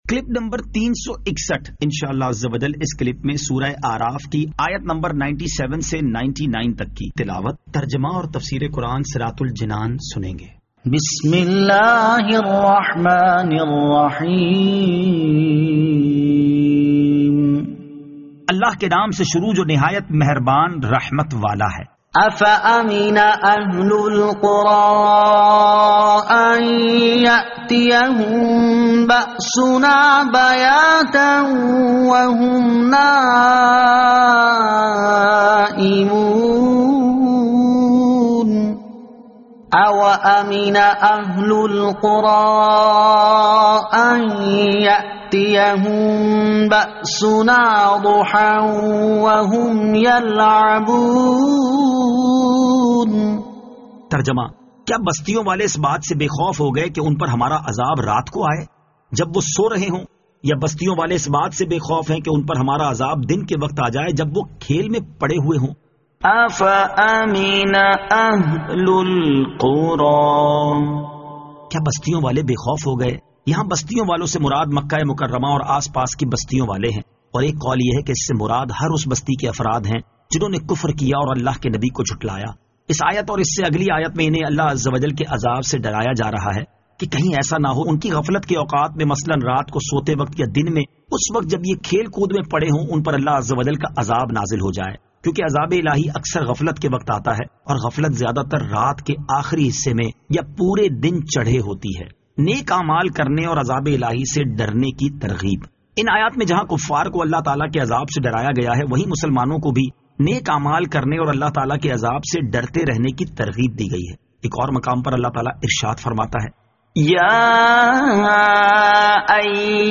Surah Al-A'raf Ayat 97 To 99 Tilawat , Tarjama , Tafseer